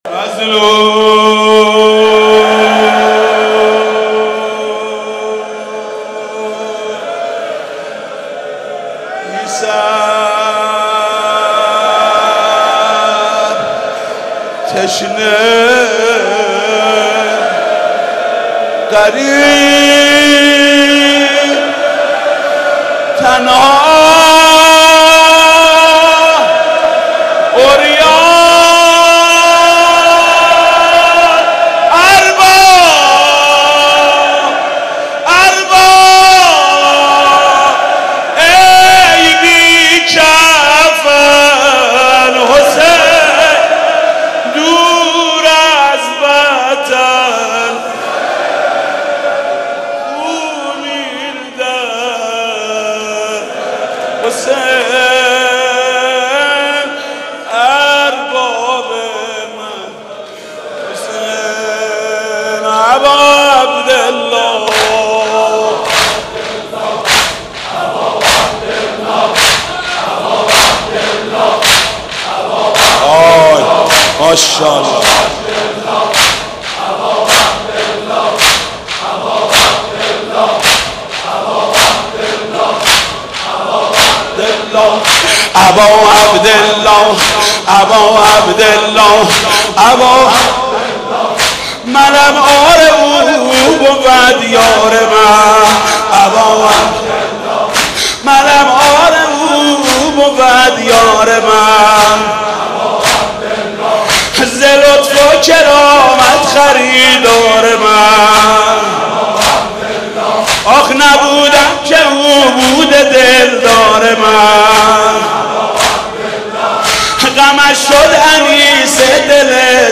نوحه امیری حسین و نعم الامیر از محمود کریمی + متن
نوحه امیری حسین و نعم الامیر از حاج محمود کریمی در شب شهادت امام هادی علیه السلام ۱۴۳۱|۱۳۸۹ - هیأت ثارالله علیه السلام